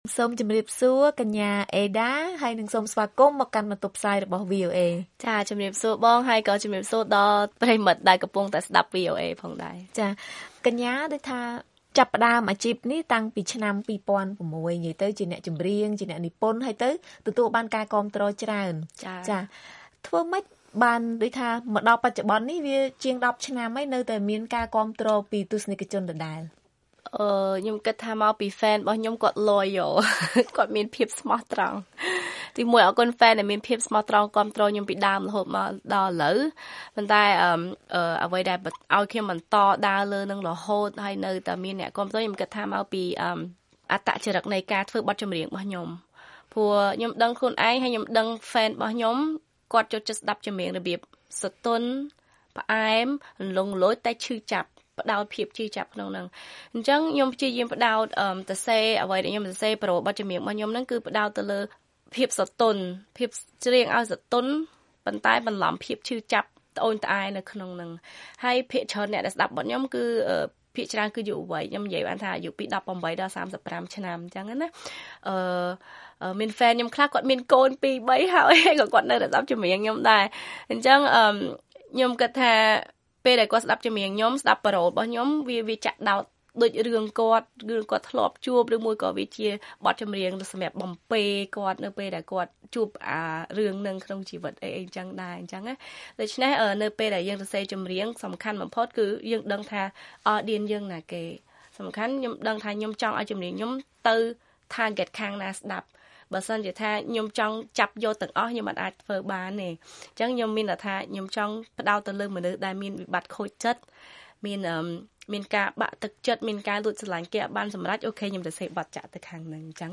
បទសម្ភាសន៍ VOA៖ ដើម្បីជោគជ័យក្នុងការនិពន្ធចម្រៀងត្រូវមានលក្ខណៈពិសេសផ្ទាល់ខ្លួន